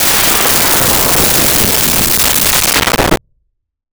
Alien Wierdness Descending 03
Alien Wierdness Descending 03.wav